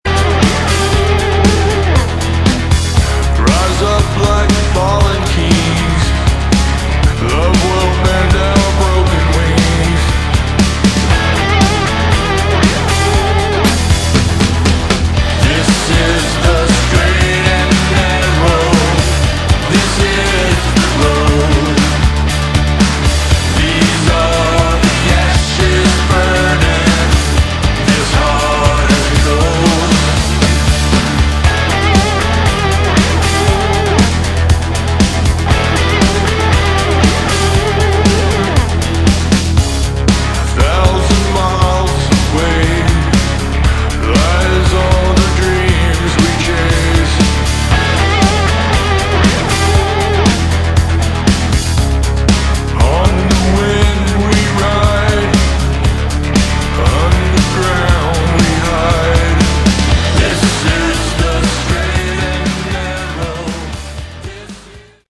Category: Hard Rock / Boogie Rock
lead vocals, guitar
lead guitars